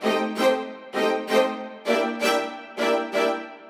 Index of /musicradar/gangster-sting-samples/130bpm Loops
GS_Viols_130-AE.wav